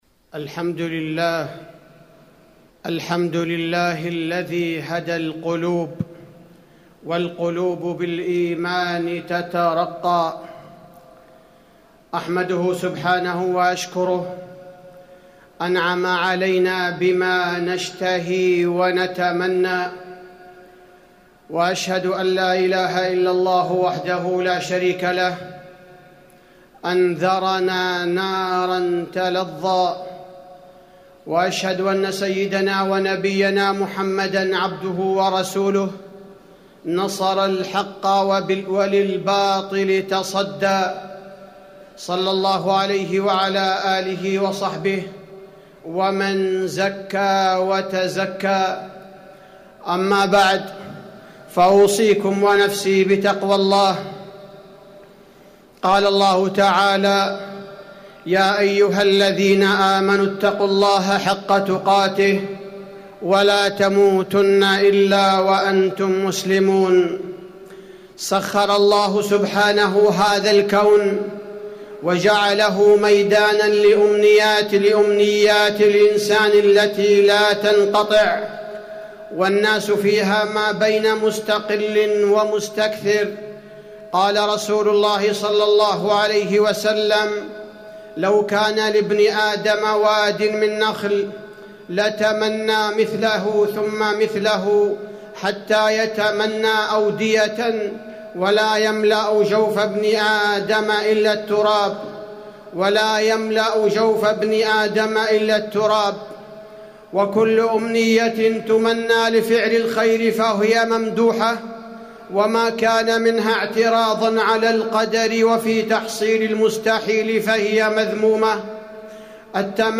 تاريخ النشر ٢٨ صفر ١٤٣٩ هـ المكان: المسجد النبوي الشيخ: فضيلة الشيخ عبدالباري الثبيتي فضيلة الشيخ عبدالباري الثبيتي الأمنيات بين المشروع والممنوع